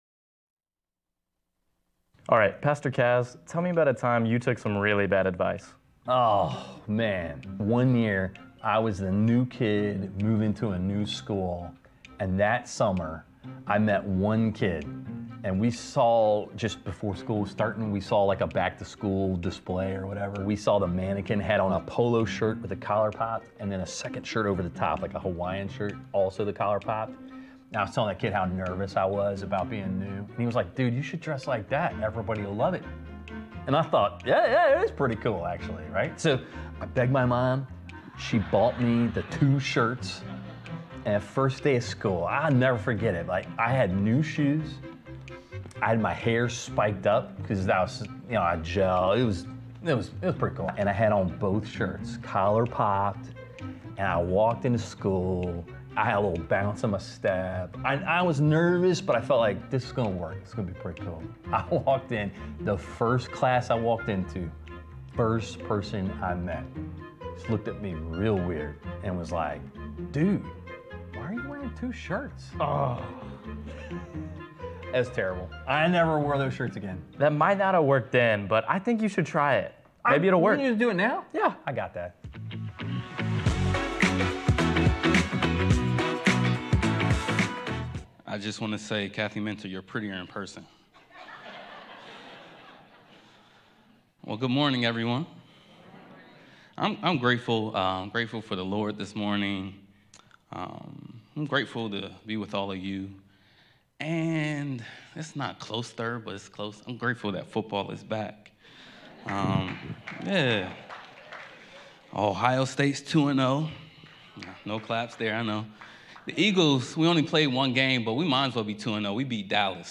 A message from the series "Bad Advice."